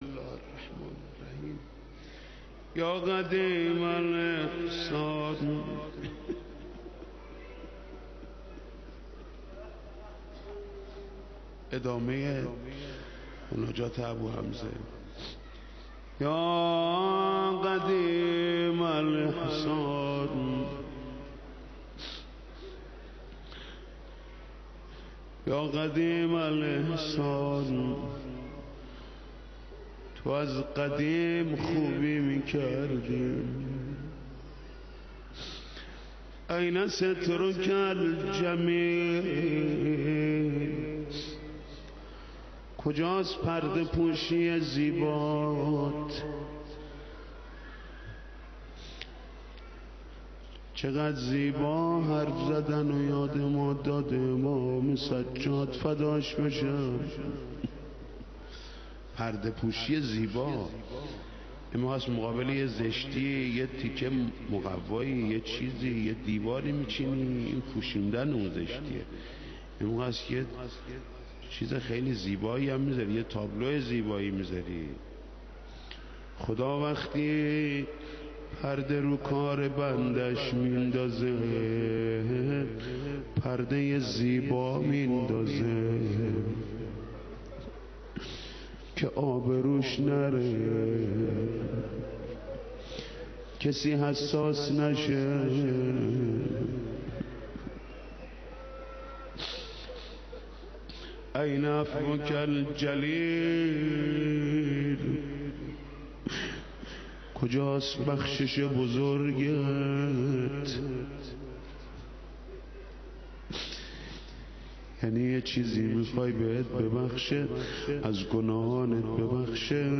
حاج محمود کریمی/مناجات
حاج محمود کریمی/روضه